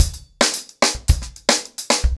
TimeToRun-110BPM.37.wav